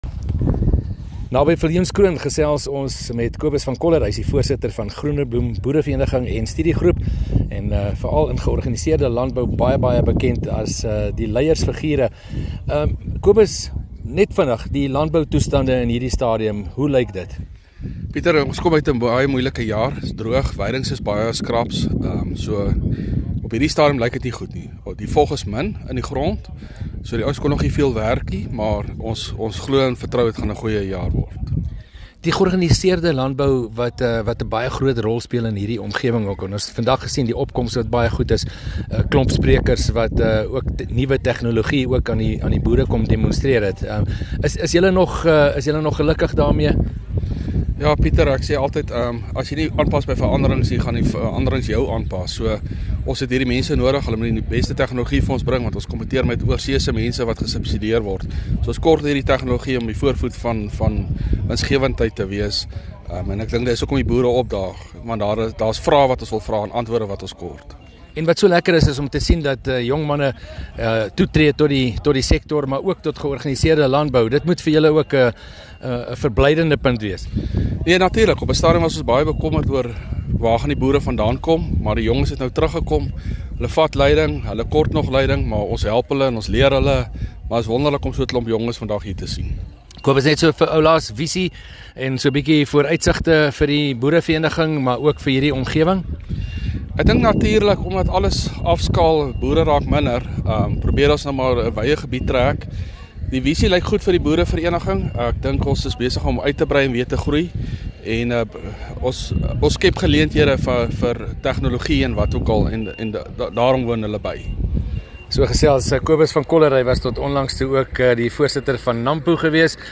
gesels met 'n leierboer